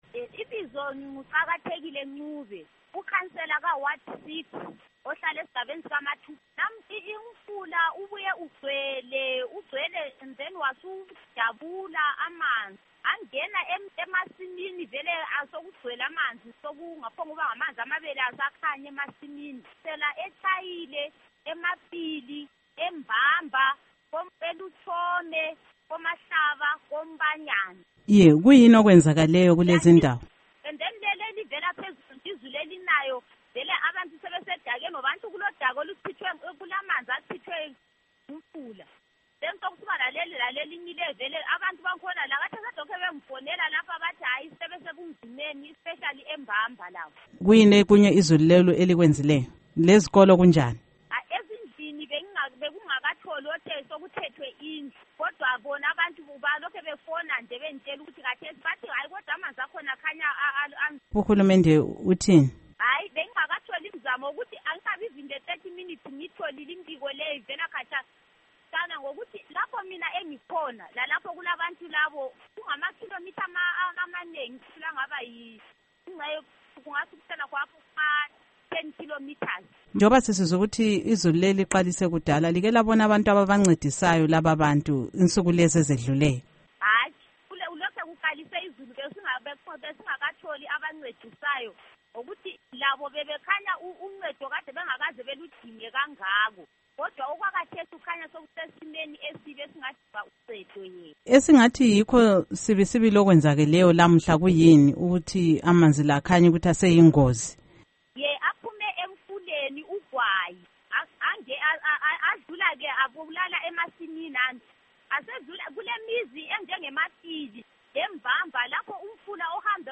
Ingxoxo LoKhansila Qakathekile Ncube